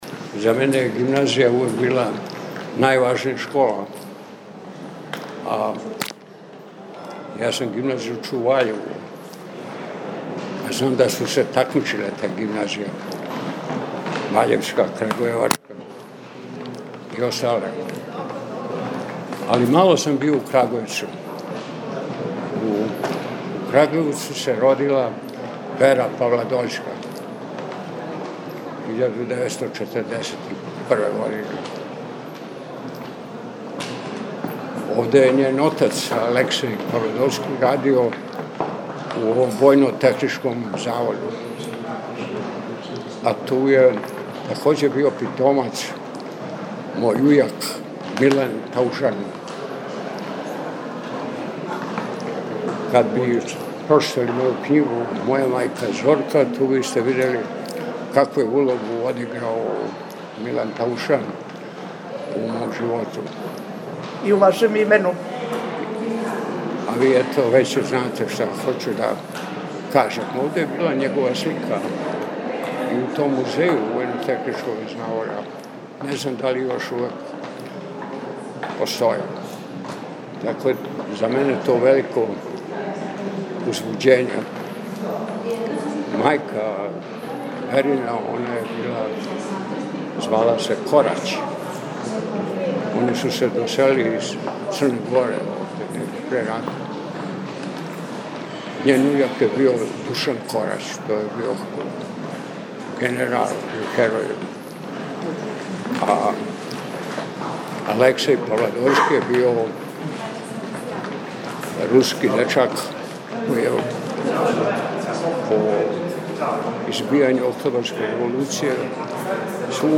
Бећковић ће своје књижевно вече одржао у свечаној сали Прве крагујевачке гимназије.
Ускоро: аудио запис књижевне вечери и уводни разговор са Матијом Бећковићем